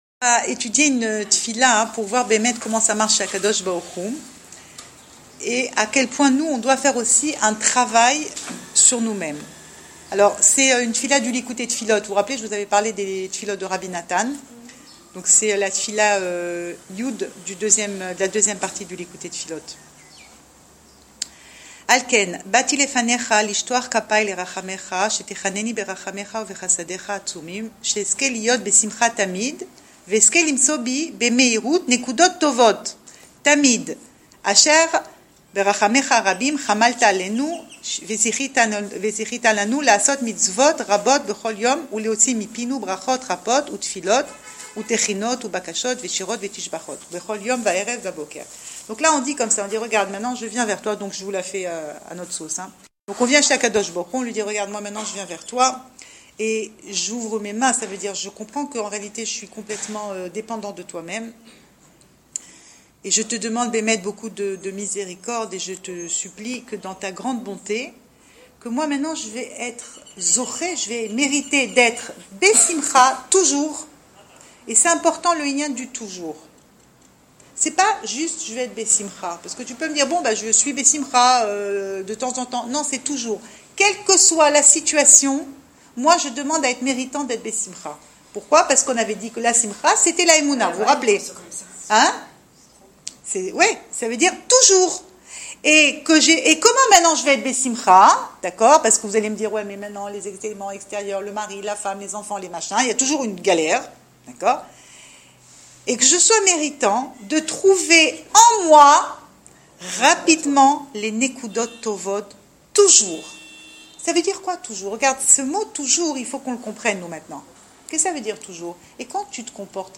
Cours audio
Enregistré à Raanana